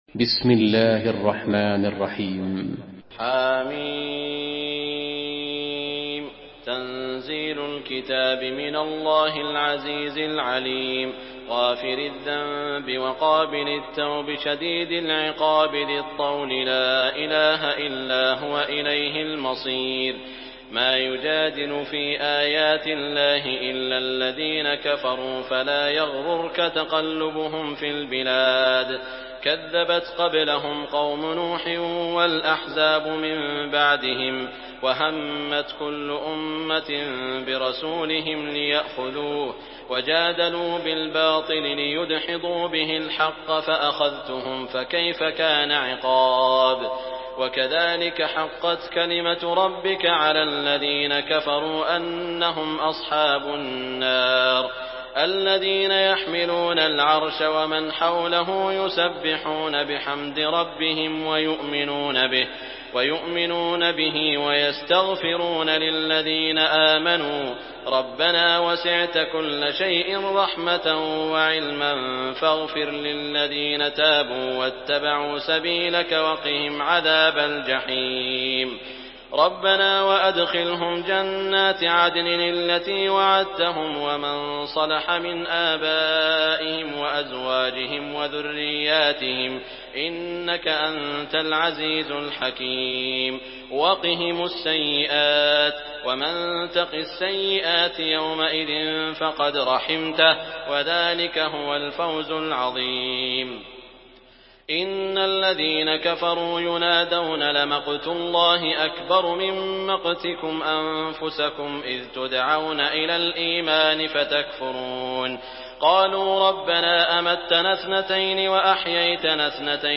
Surah Ghafir MP3 in the Voice of Saud Al Shuraim in Hafs Narration
Murattal Hafs An Asim